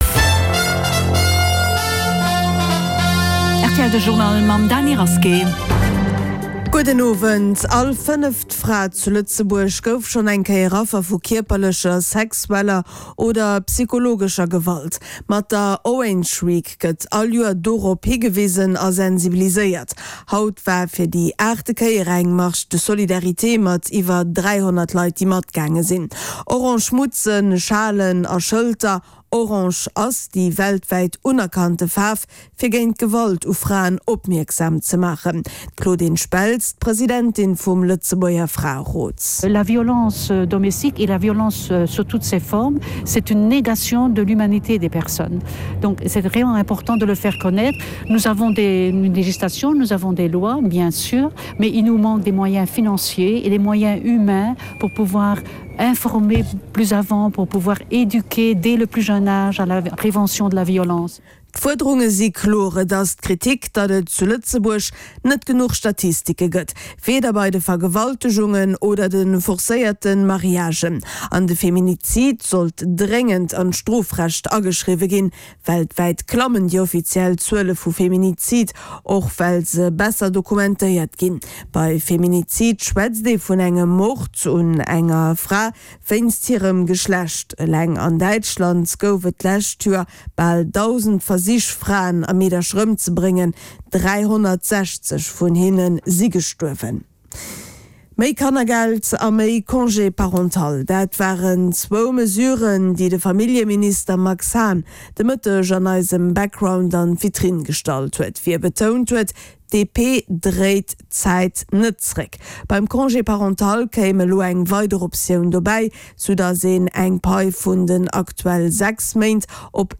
De grousse Journal vun RTL Radio Lëtzebuerg, mat Reportagen, Interviewën, Sport an dem Round-up vun der Aktualitéit, national an international